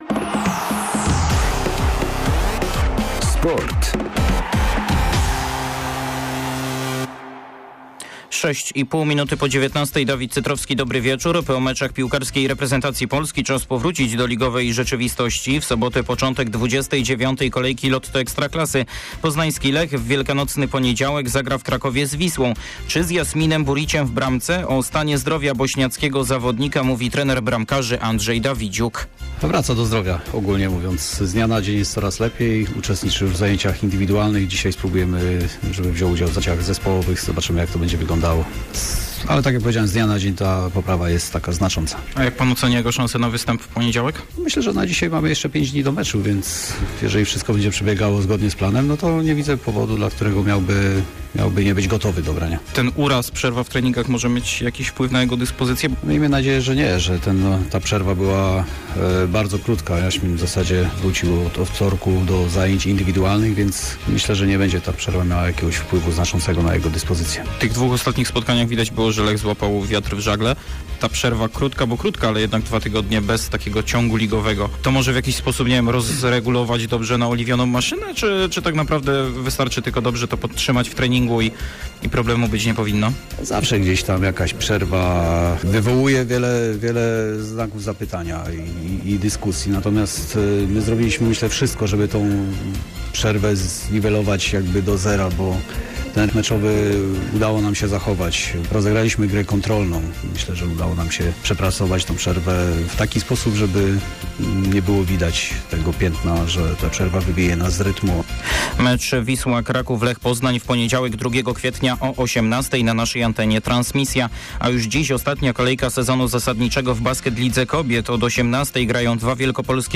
28.03 serwis sportowy godz. 19:05